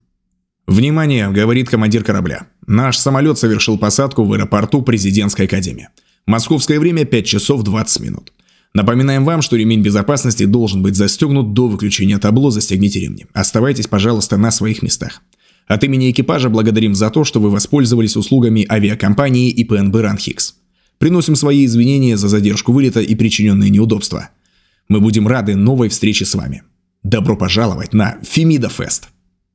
Анонс мероприятия
Муж, Другая/Зрелый